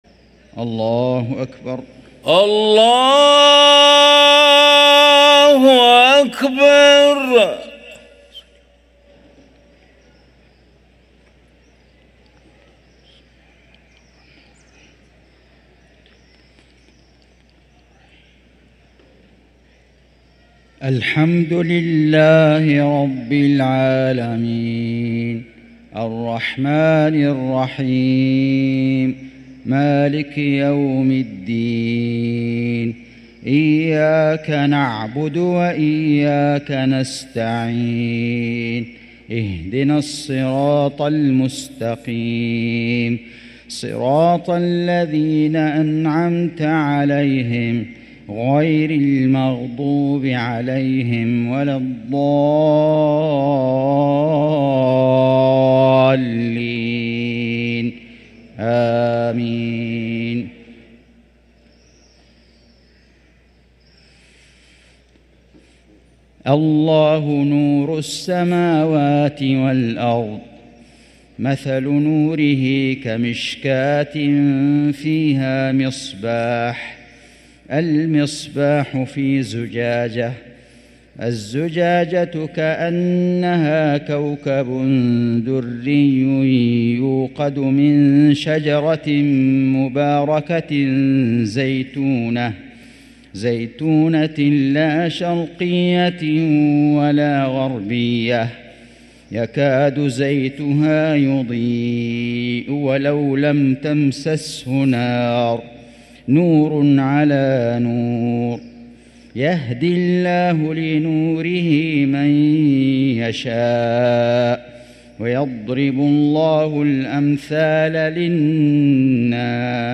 صلاة العشاء للقارئ فيصل غزاوي 6 رجب 1445 هـ
تِلَاوَات الْحَرَمَيْن .